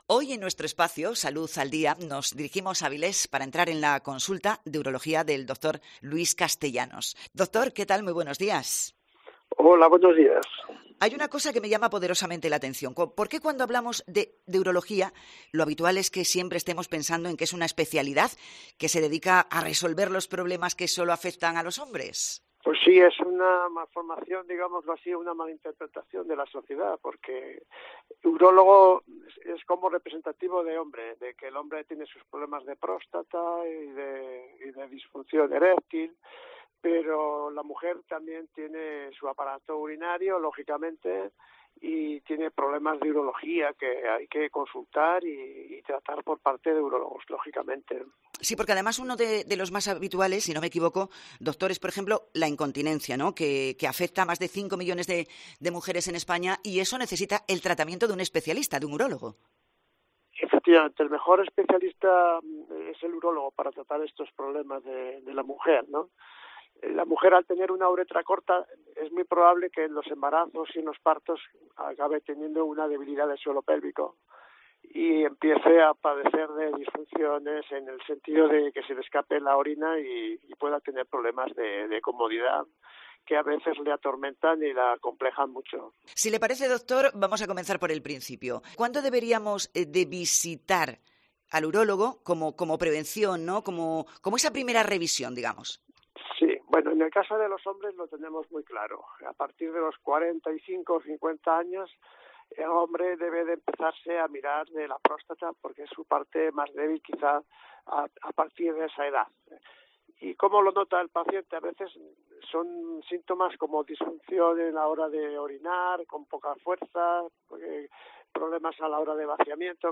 Salud al Día en COPE: entrevista